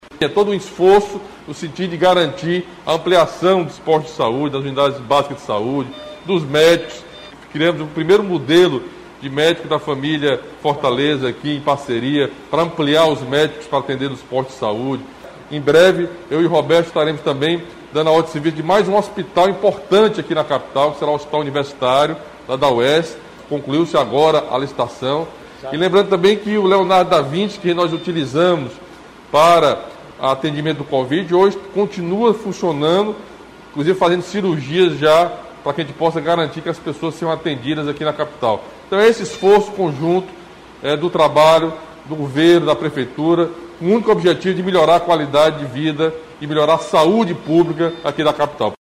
O governador Camilo Santana destaca o esforço do Governo do Ceará e da Prefeitura de Fortaleza para buscar a melhoria do acesso à saúde na capital.